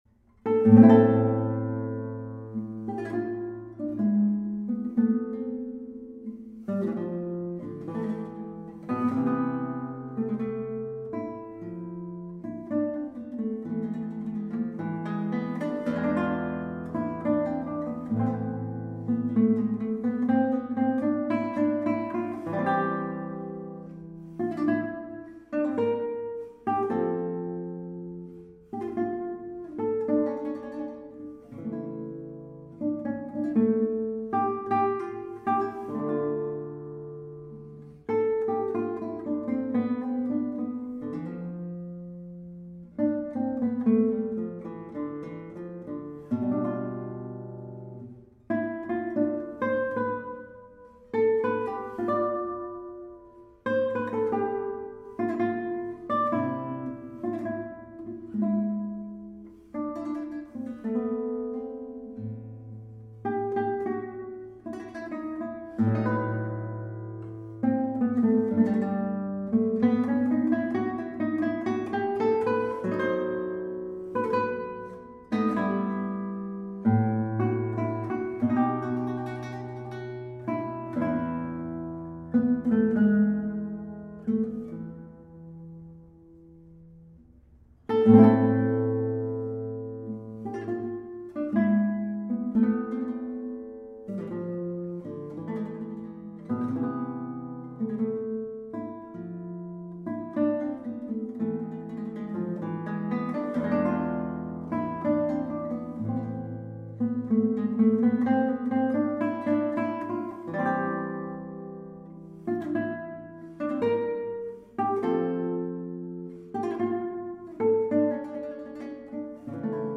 Guitarra Clásica